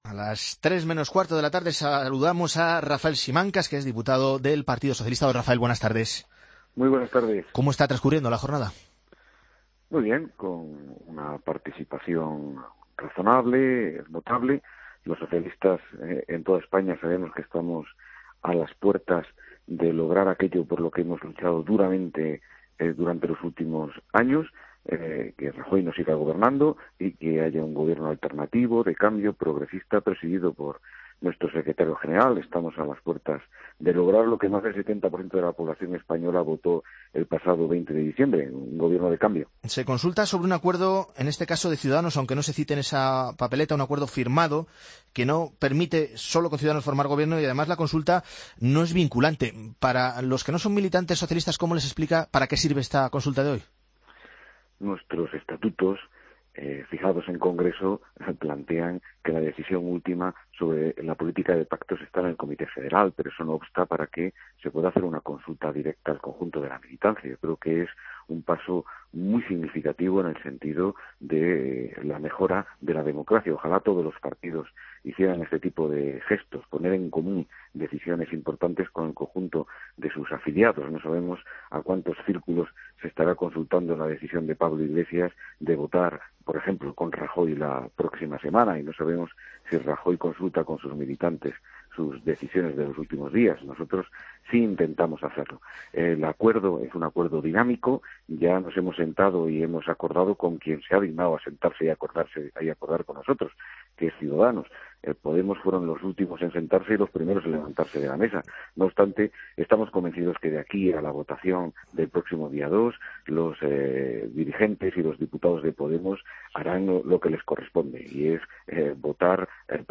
AUDIO: Escucha la entrevista al diputado del PSOE Rafael Simancas en Mediodía COPE.